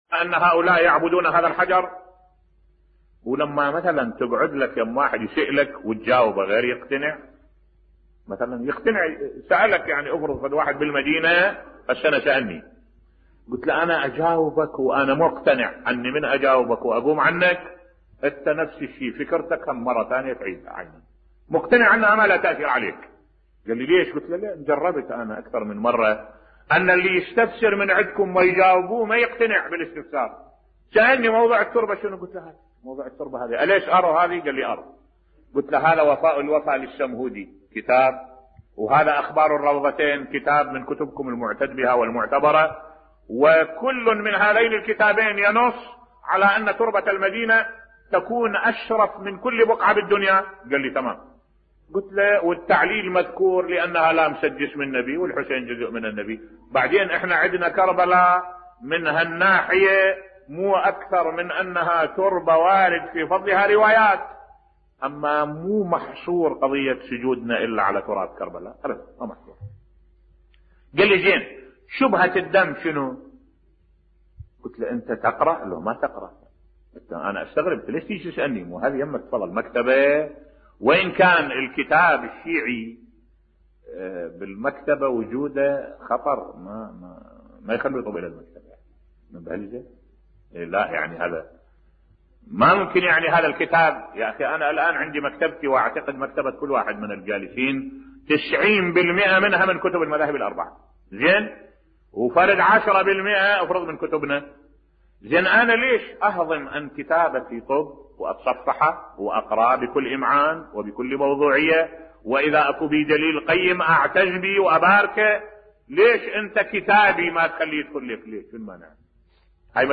ملف صوتی جواب الشيخ أحمد الوائلي لأحدهم في المدينة المنورة حول التربة الحسينية بصوت الشيخ الدكتور أحمد الوائلي